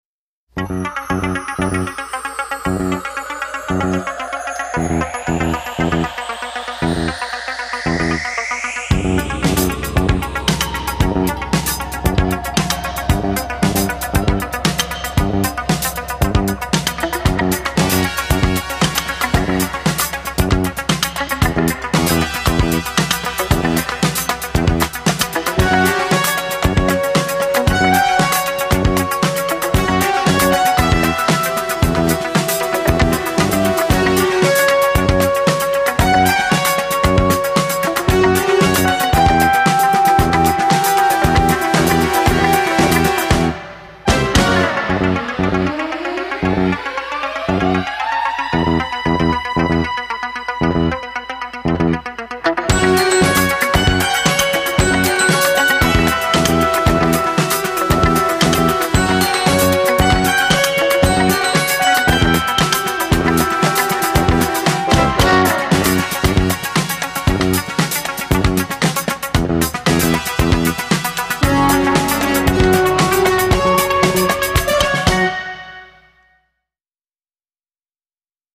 Sigla tv originale (1.05 Mb)